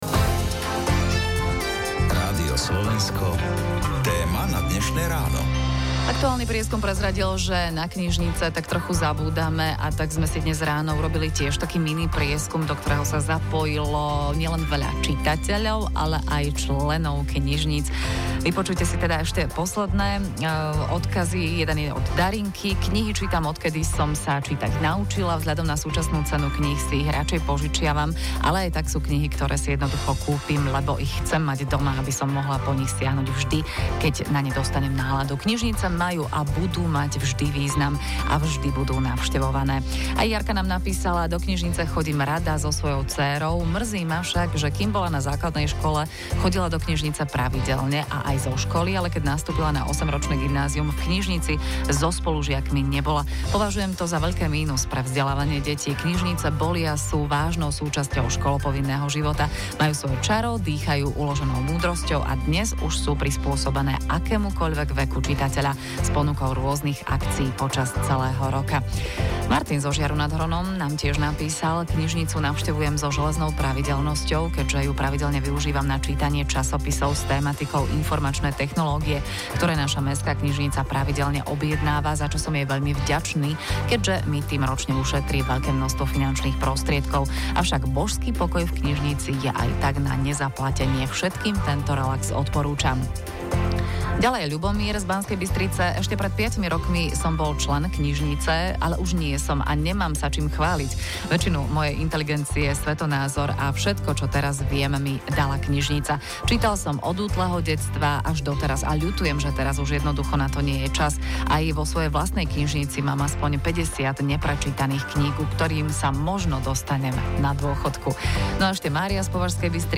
Pravidelný ranný magazín rozhlasového okruhu Rádio Slovensko
Reláciu redaktorsky pripravila a moderovala